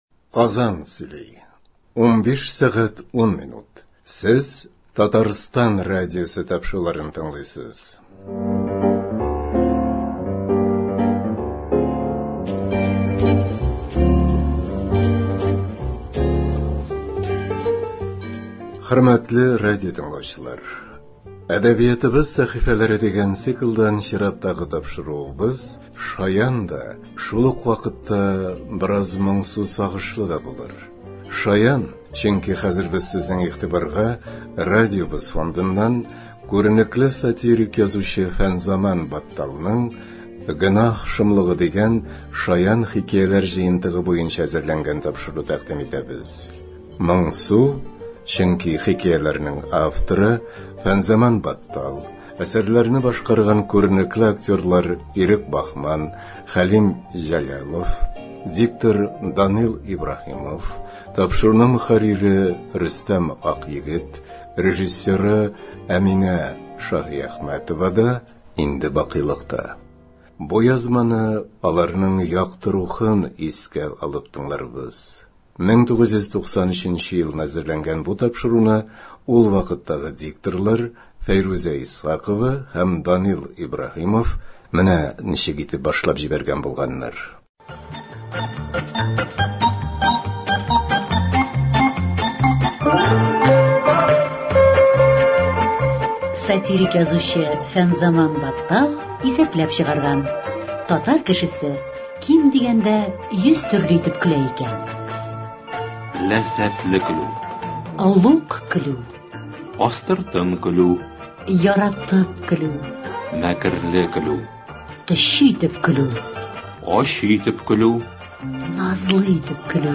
«Әдәбиятыбыз сәхифәләре» дигән циклдан чираттагы тапшыруыбыз шаян да, шул ук вакытта бераз моңсу-сагышлы да булачак. Шаян, чөнки хәзер без сезнең игътибарга радиобыз фондыннан күренекле сатирик-язучы Фәнзаман Батталның “Гөнаһ шомлыгы” дигән шаян хикәяләр җыентыгы буенча әзерләнгән тапшыру тәкъдим итәбез.